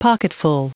Prononciation du mot pocketful en anglais (fichier audio)